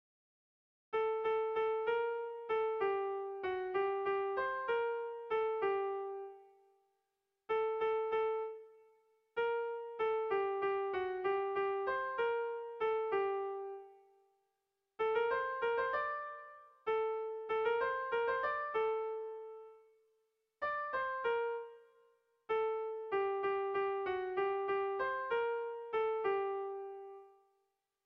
Dantzakoa